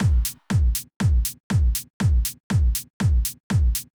Drumloop 120bpm 05-C.wav